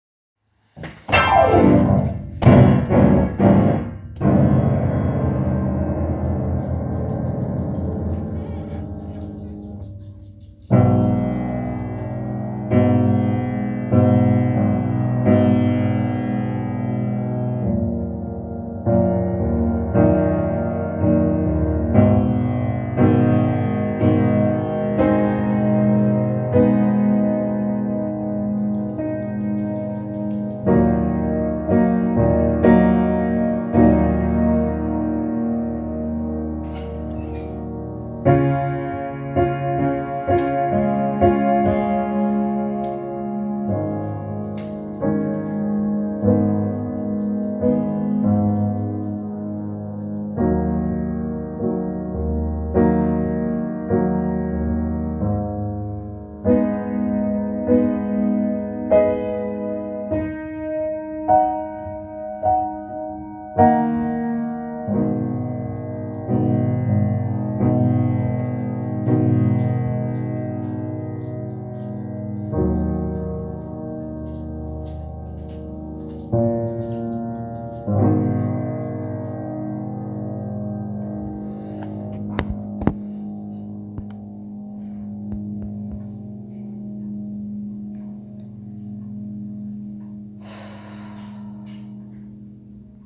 Piano improvisation with intention of “feeling the emotions of being in the midst of a Pandemic”